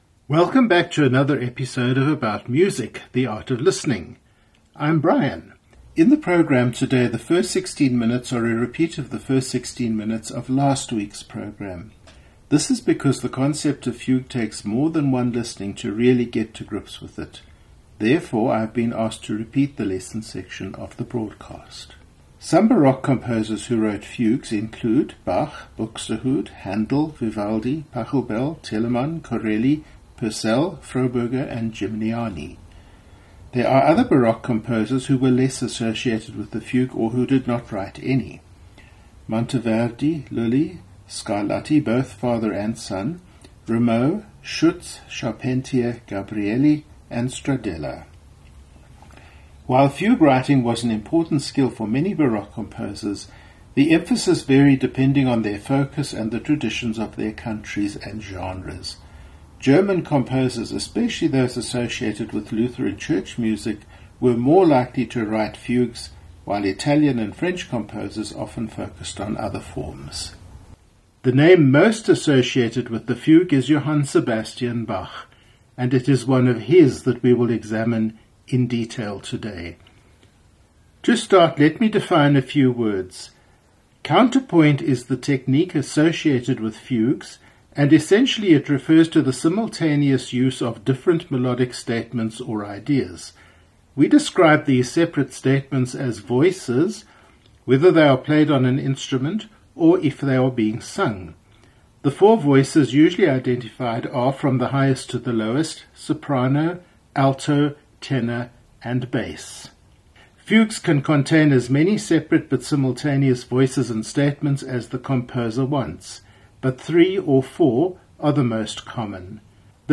This week the music is not only for piano – I have fugues for Choir, Organ, Chamber Orchestra, Lute, Violin and Piano.